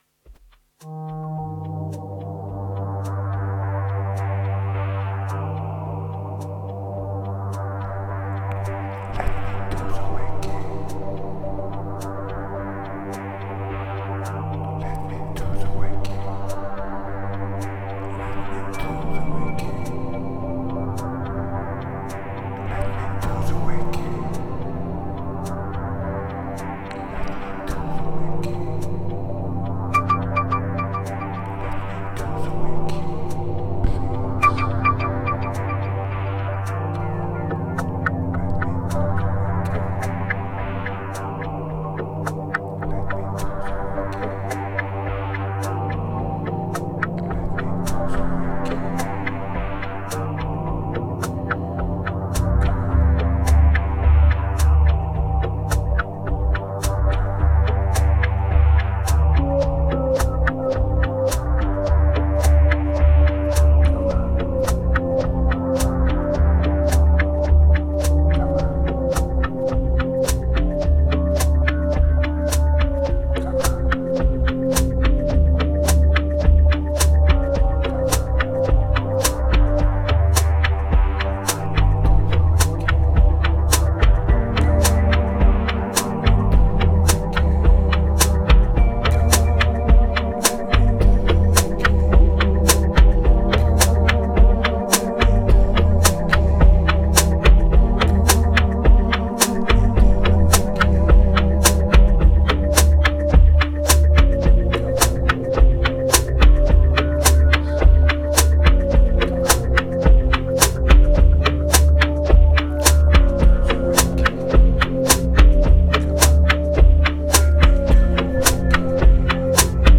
3183📈 - 15%🤔 - 107BPM🔊 - 2016-04-07📅 - -337🌟